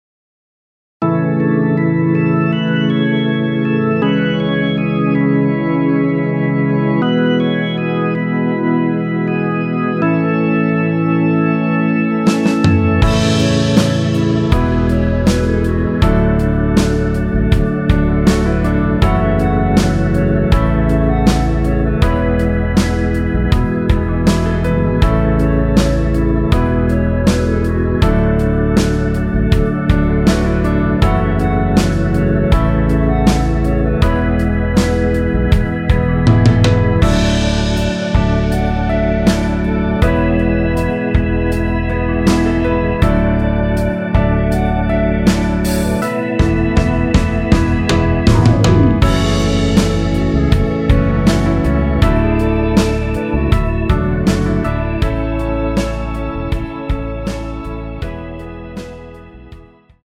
원키에서(-6)내린 멜로디 포함된 1절후 바로 후렴으로 진행 됩니다.(아래의 가사를 참조하세요)
노래방에서 노래를 부르실때 노래 부분에 가이드 멜로디가 따라 나와서
앞부분30초, 뒷부분30초씩 편집해서 올려 드리고 있습니다.
중간에 음이 끈어지고 다시 나오는 이유는